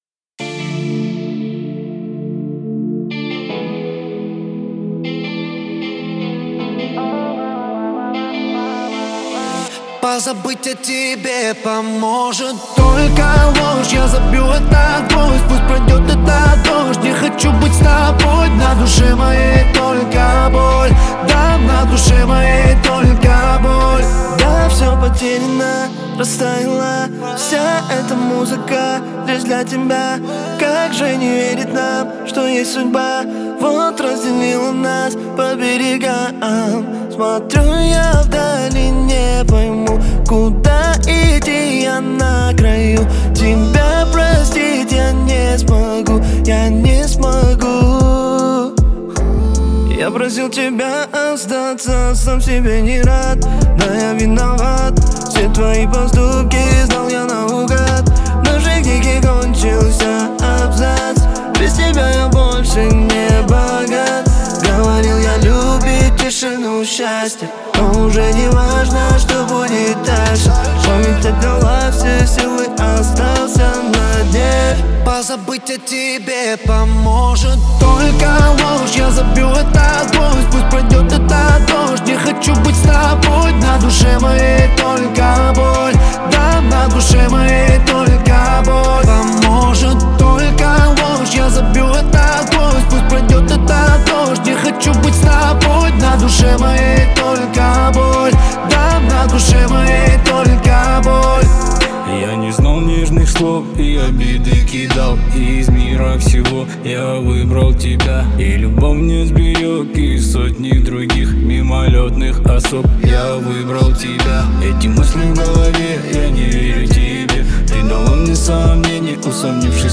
выполненная в жанре хип-хоп с элементами трэпа.